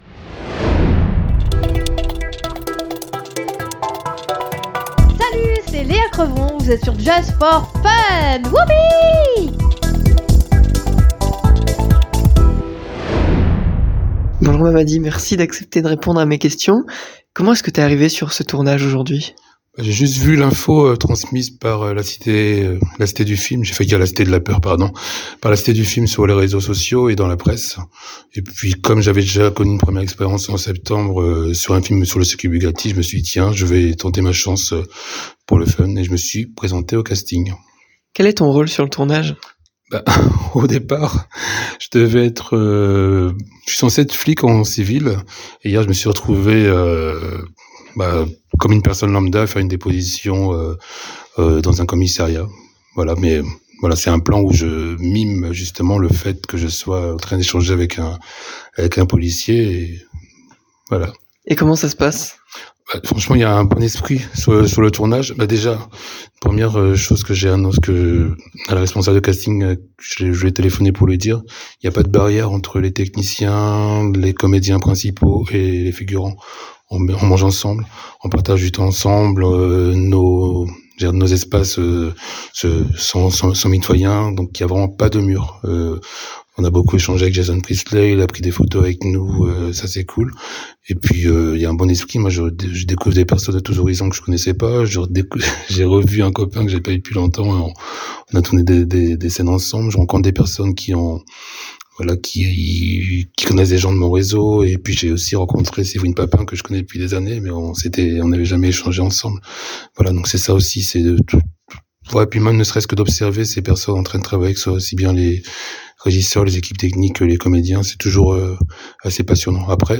Just4Fun vous emmène dans les coulisses du tournage du film "Mort sur la piste", à travers des interviews des figurants